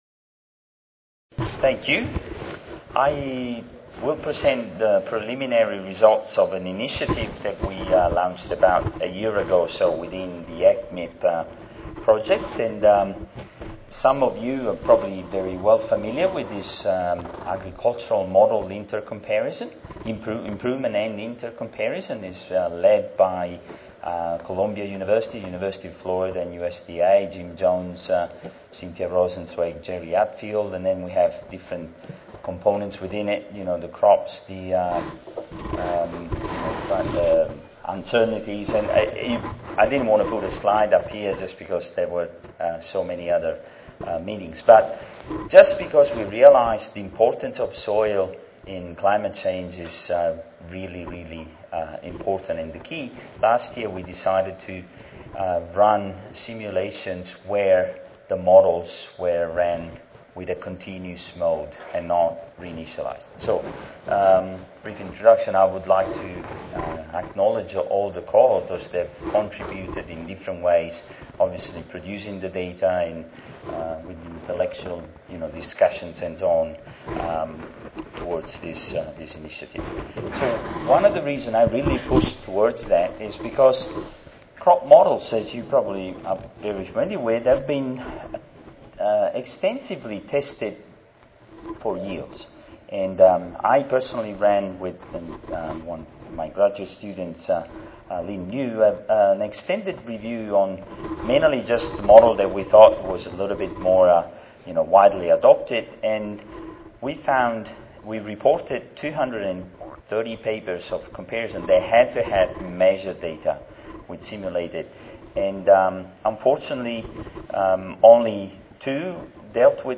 Queensland University of Technology Audio File Recorded Presentation